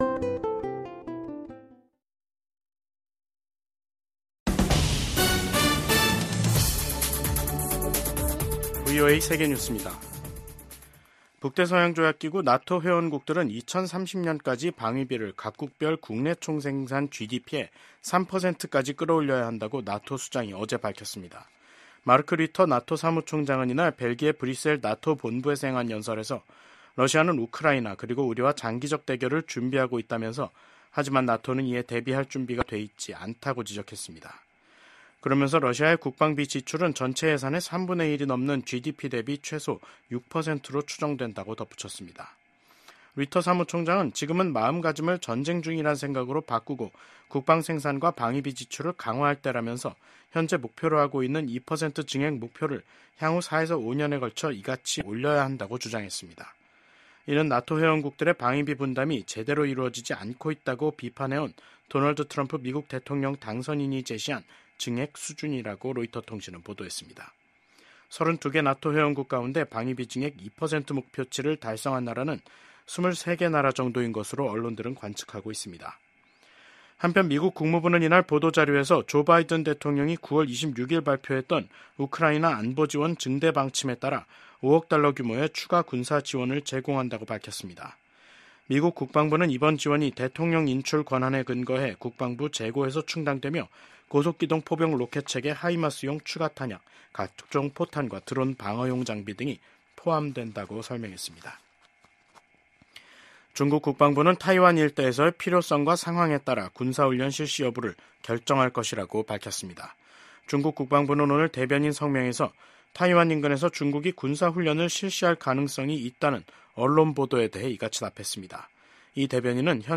세계 뉴스와 함께 미국의 모든 것을 소개하는 '생방송 여기는 워싱턴입니다', 2024년 12월 13일 저녁 방송입니다. 이스라엘을 방문한 제이크 설리번 미국 백악관 국가안보보좌관이 가자지구 휴전과 인질 석방 협상이 곧 타결될 가능성이 있다고 말했습니다. 조 바이든 미국 대통령이 퇴임 전 39명의 사면과 약 1천500명에 대한 감형 조치를 단행했습니다. 크리스토퍼 레이 미 연방수사국(FBI) 국장이 내년 1월 새 정부 취임과 함께 사퇴하겠다고 밝혔습니다.